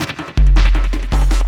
53 LOOP 06-R.wav